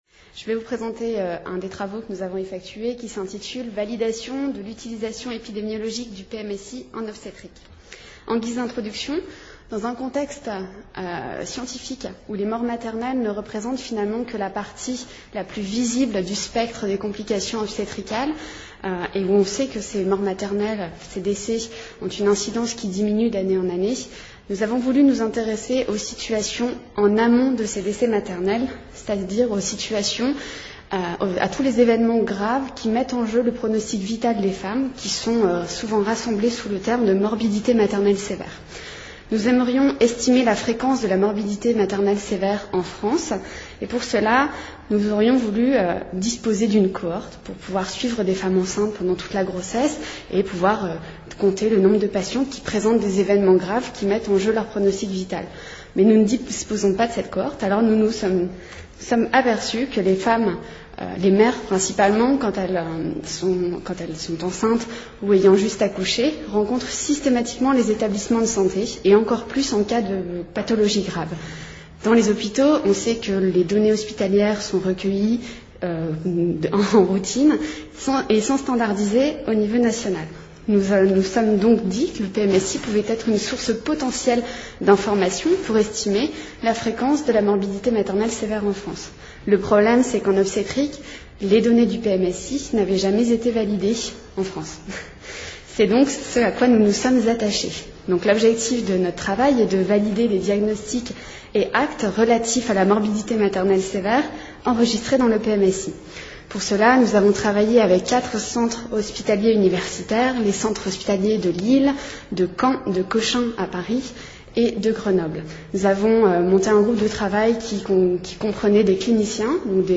Congrès ADELF-EMOIS 2010 Au-delà des fins budgétaires, l’exploitation des données de l’information médicale et en particulier du Programme de Médicalisation des Systèmes d’Information (PMSI) est croissante dans le cadre des prévisions d’activité et pour les décisions stratégiques d’investissement des établissements de santé.